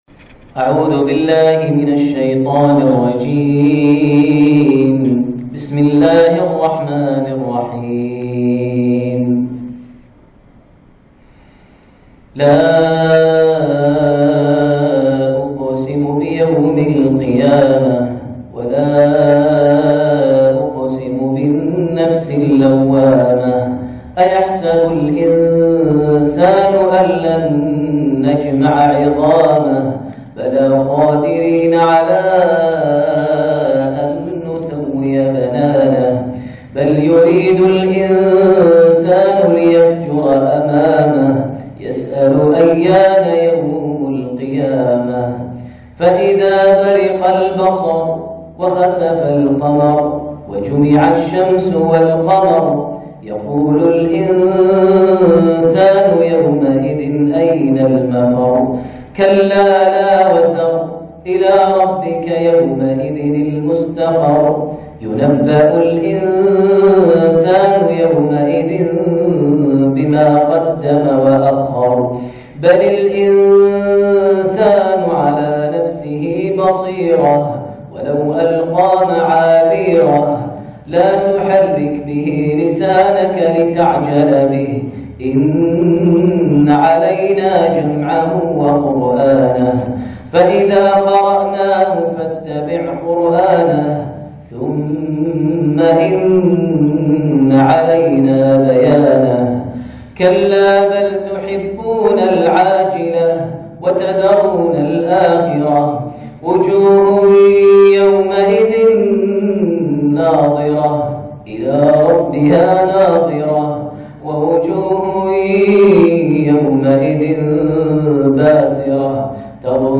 تلاوة بديعة لسورة القيامة عام ١٤٣٠هـ من دولة الإمارات > زيارة الشيخ ماهر المعيقلي لدولة الإمارات ١٤٣٥هـ > المزيد - تلاوات ماهر المعيقلي